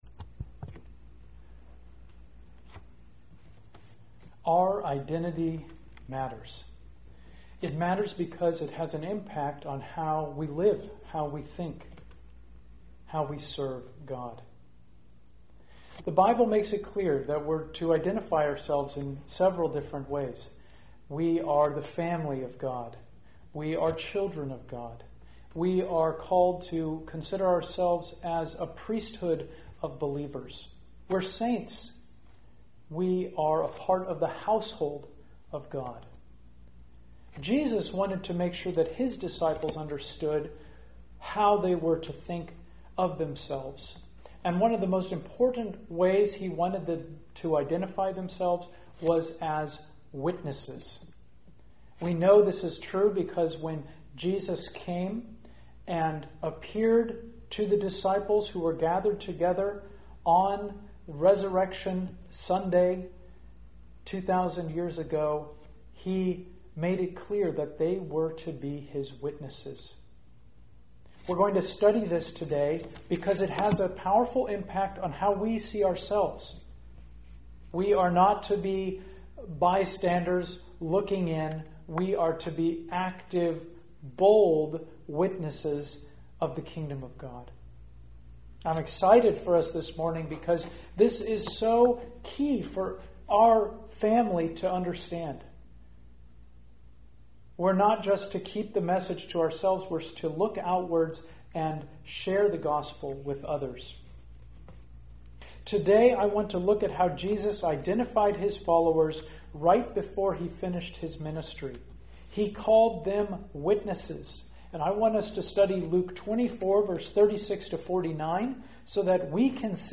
All Sermons A Bold Witness for Christ